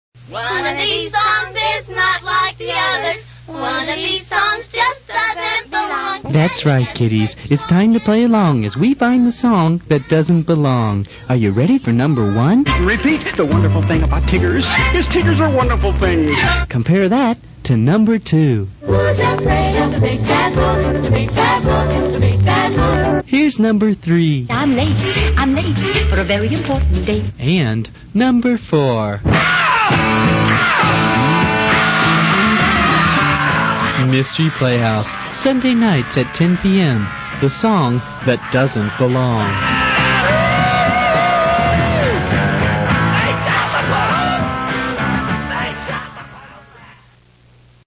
All these recordings were made at WEGL, Auburn University.
[The remaining promos never got a connecting storyline and were packaged seperately.]